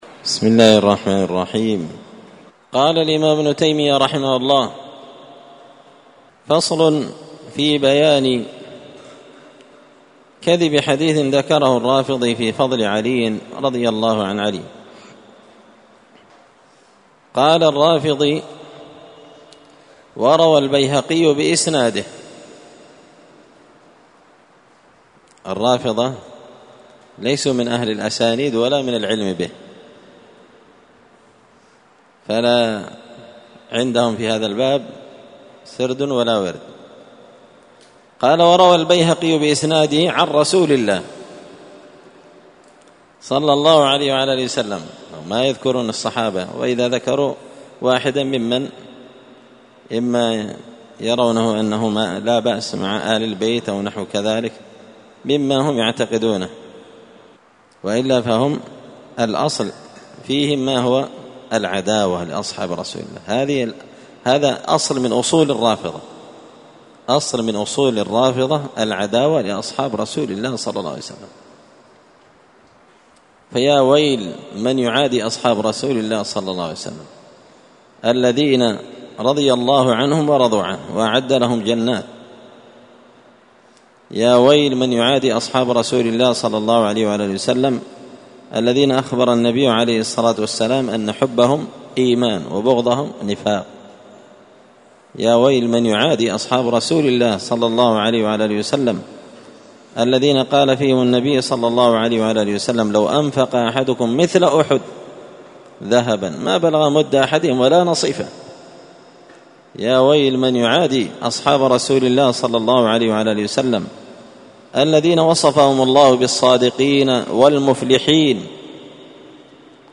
الأربعاء 25 ذو القعدة 1444 هــــ | الدروس، دروس الردود، مختصر منهاج السنة النبوية لشيخ الإسلام ابن تيمية | شارك بتعليقك | 8 المشاهدات
مسجد الفرقان قشن_المهرة_اليمن